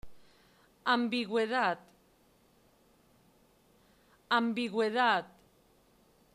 Haz clic en la palabra para oír la pronunciación (variante castellana)
/g/ ga gue gui go gu güe güi